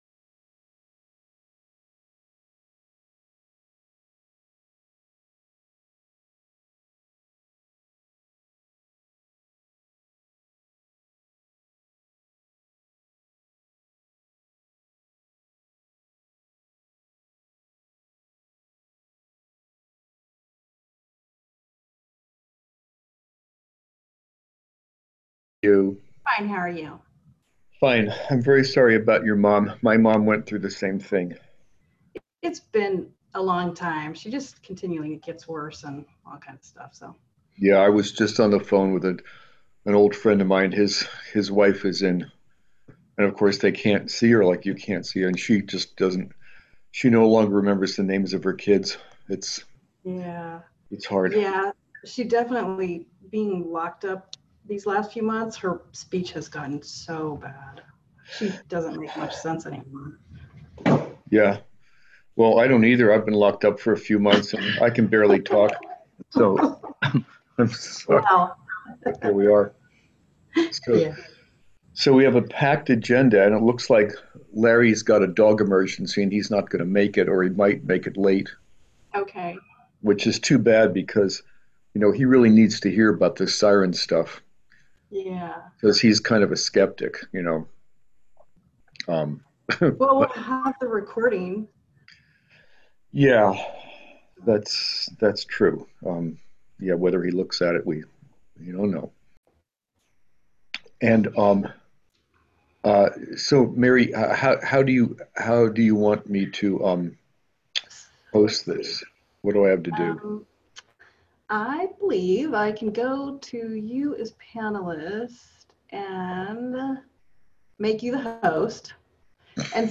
Emergency Preparedness Committee Meeting